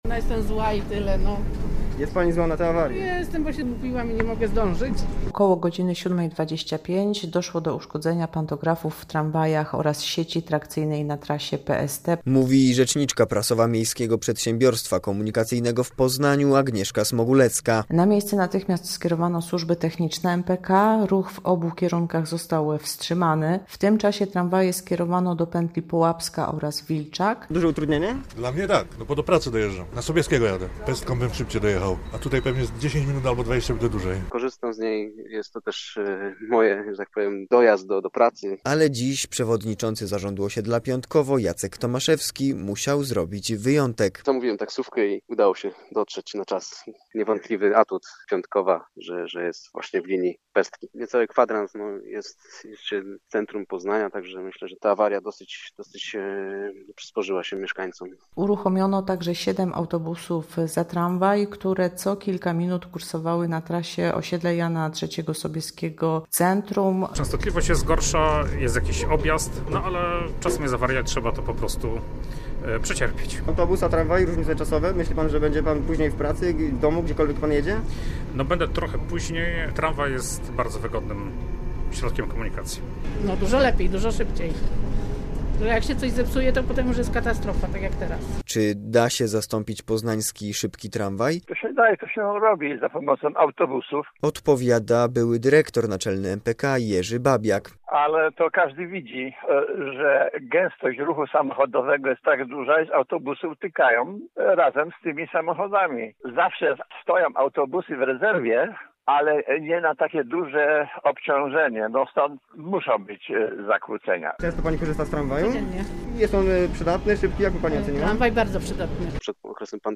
SERWIS INFORMACYJNY